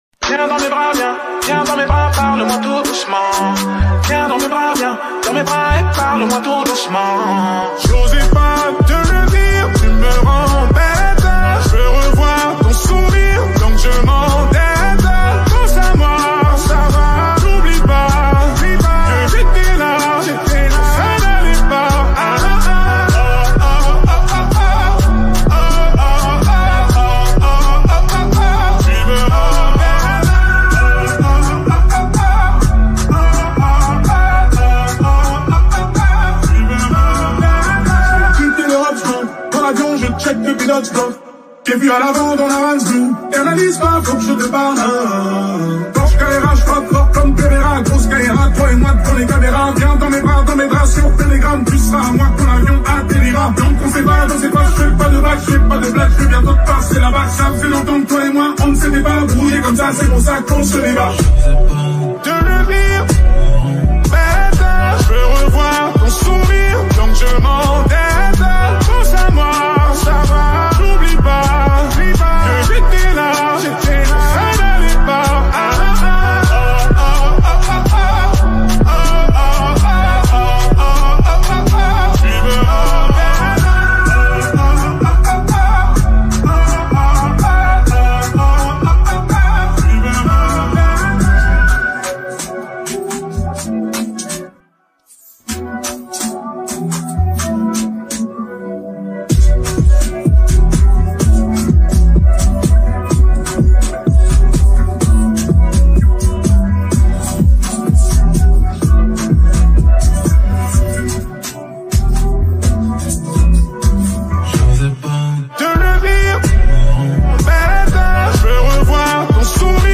| Afro pop